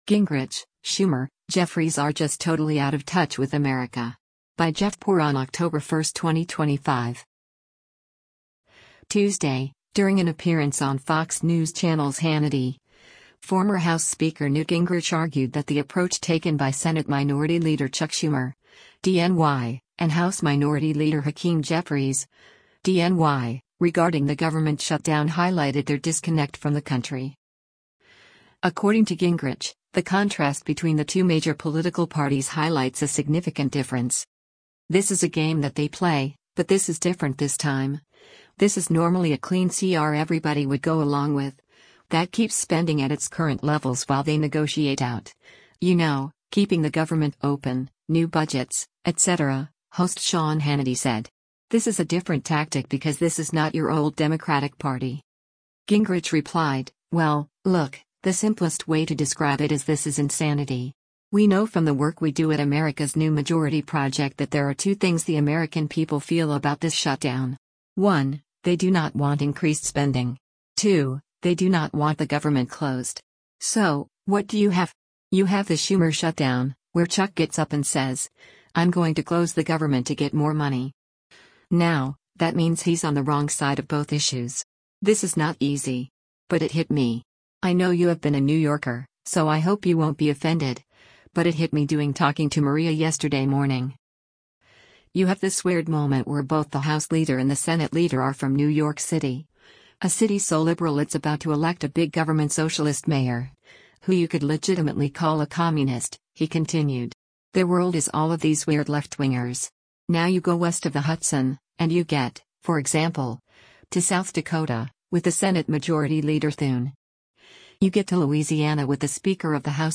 Tuesday, during an appearance on Fox News Channel’s “Hannity,” former House Speaker Newt Gingrich argued that the approach taken by Senate Minority Leader Chuck Schumer (D-NY) and House Minority Leader Hakeem Jeffries (D-NY) regarding the government shutdown highlighted their disconnect from the country.